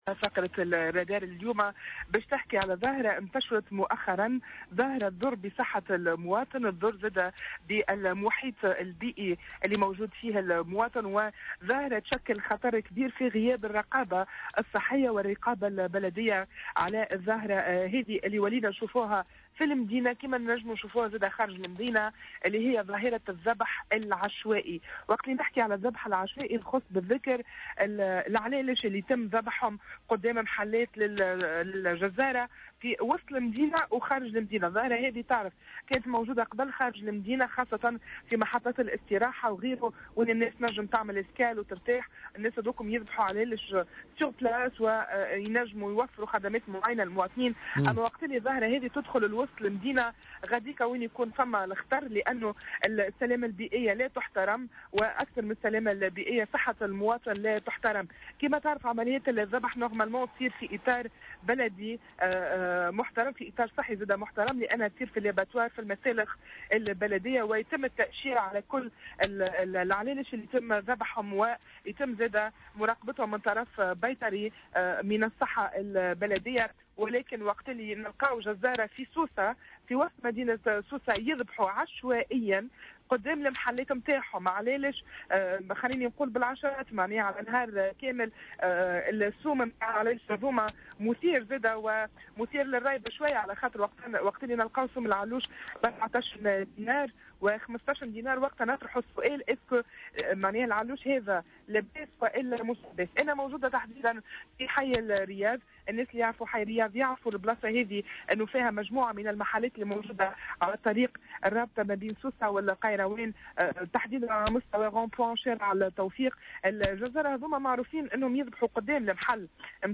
وقد تحوّل فريق فقرة "الرادار" اليوم الأربعاء إلى منطقة حي الرياض من مدينة سوسة، أين تمت معاينة جملة من الإخلالات، على مستوى الطريق الرابط بين سوسة و القيروان وتحديدا بمفترق شارع التوفيق ، حيث عمد عدد من أصحاب المحلات على ذبح المواشي في الشارع عوضا عن المسالخ البلدية وبيعها بأسعار "مغرية" و دون أن تخضع للمراقبة البيطرية .